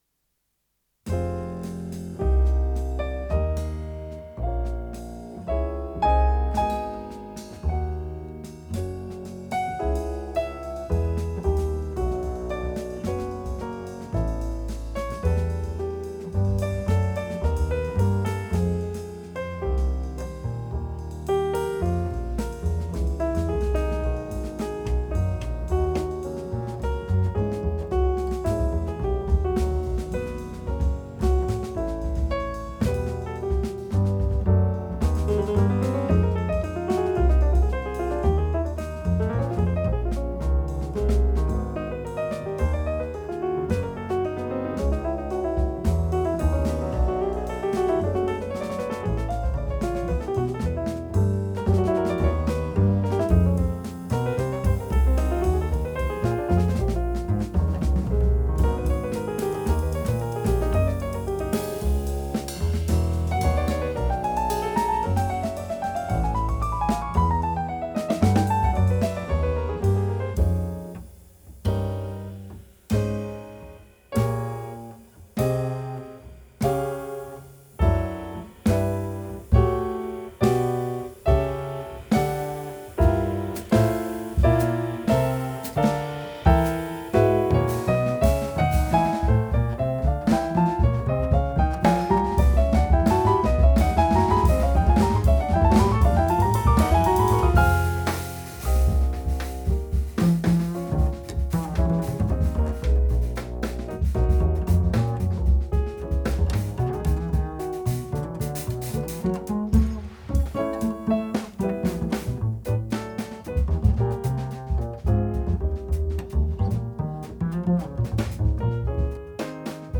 Klavier
Kontrabass
Schlagzeug